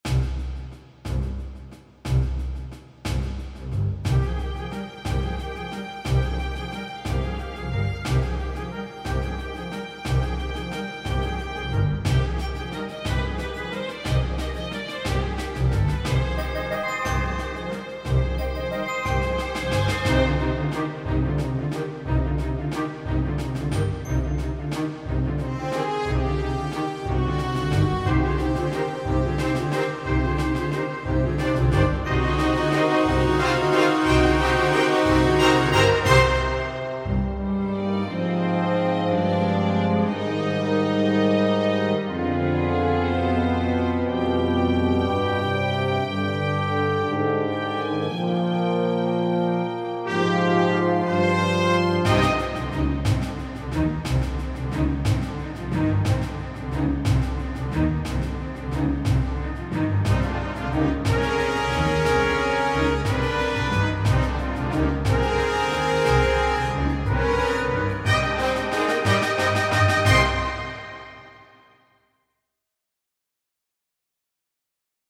描述：电影|激越
Tag: 小号 弦乐器